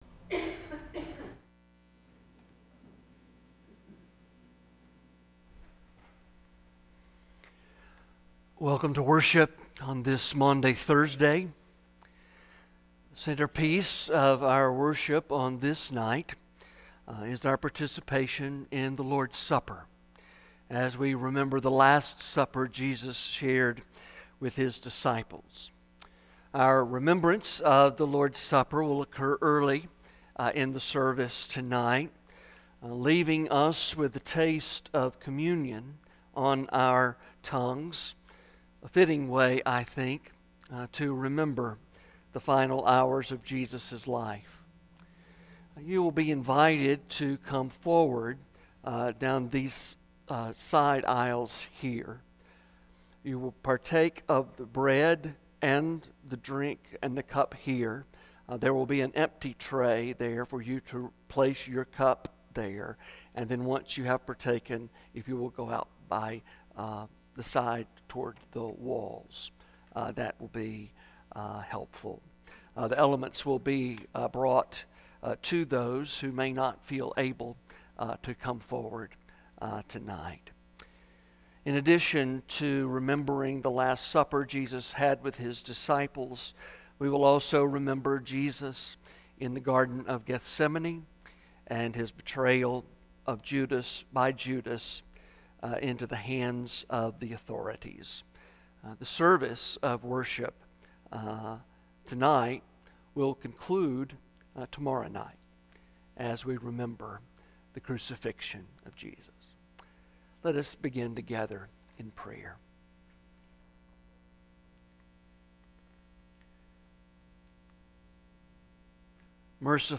Maundy Thursday Service